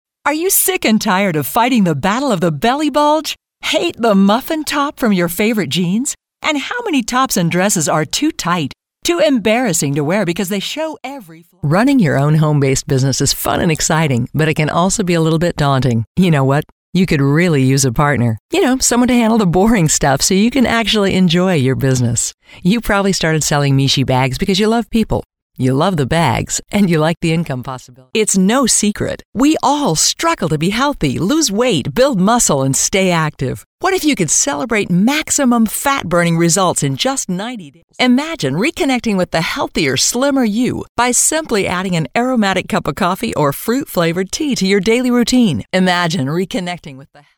Female Voice Over, Dan Wachs Talent Agency.
Professional, polished, believable
Infomercial